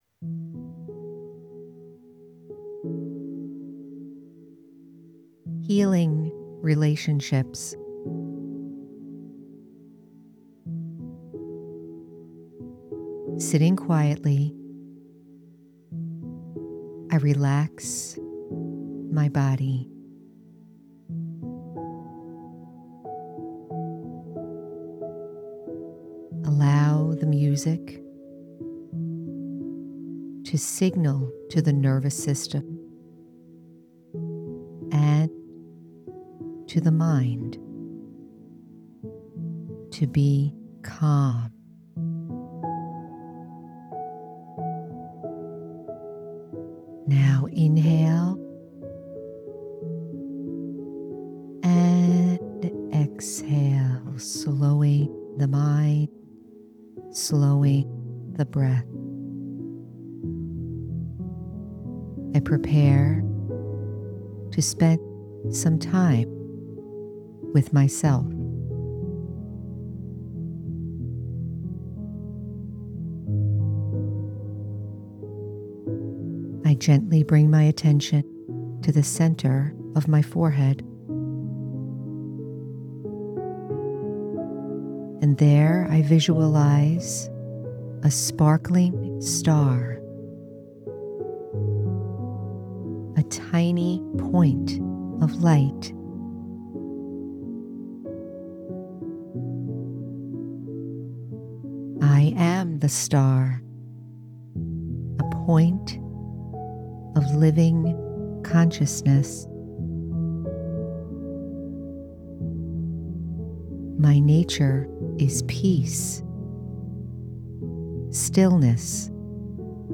Healing Relationships- Guided Meditation- The Spiritual American- Episode 152